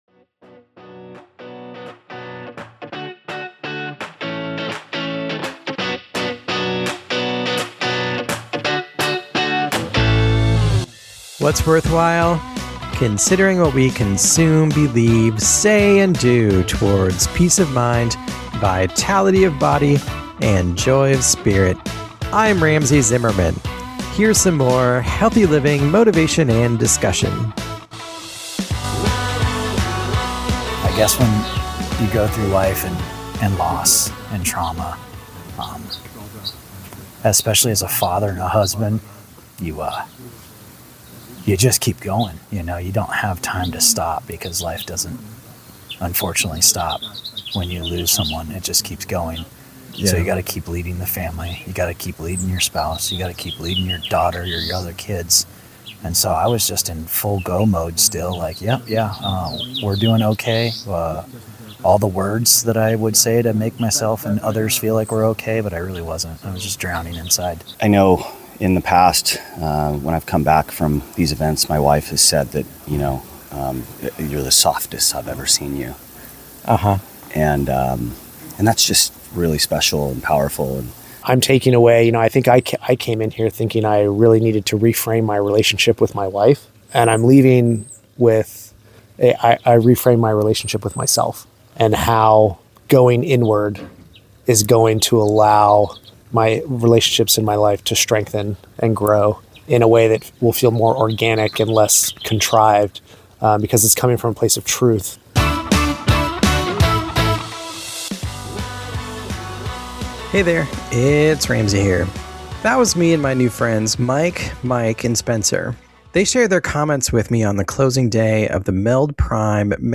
Listen in on their raw reflections of expectations far exceeded, insights gained, and images of transformation glimpsed.